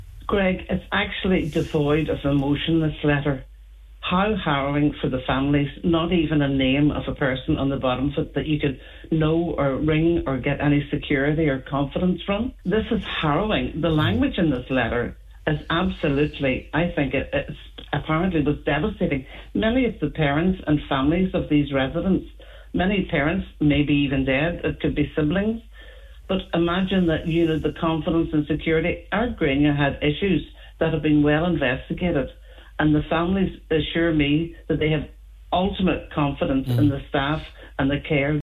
Speaking on this mornings Nine ’til Noon Show